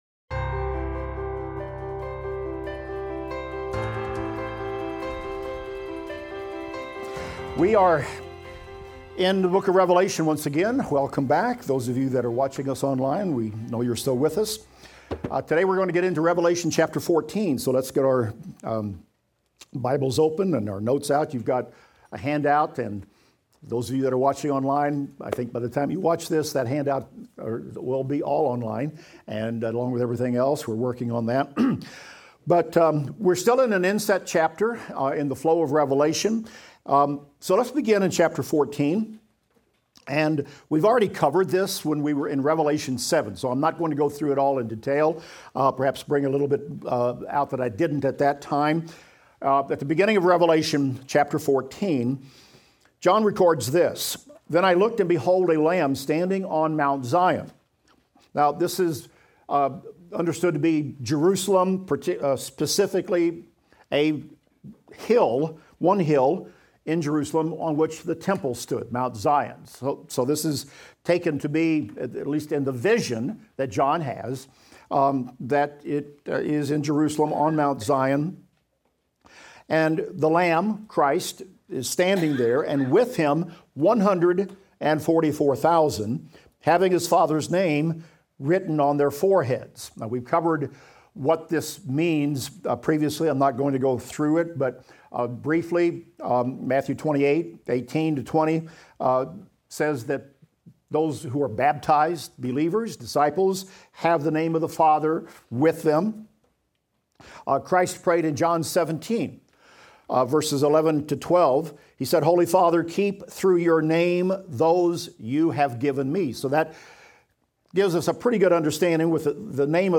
Revelation - Lecture 45 - audio.mp3